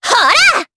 Dosarta-Vox_Attack3_jp.wav